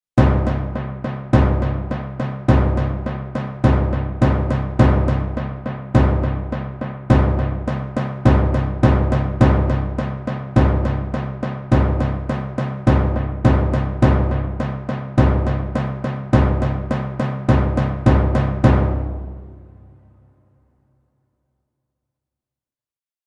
Intense Cinematic Timpani Drums Sound Effect
Description: Intense cinematic timpani drums sound effect. Powerful timpani drums create intense tension and drive drama in your cinematic scenes.
Genres: Sound Effects
Intense-cinematic-timpani-drums-sound-effect.mp3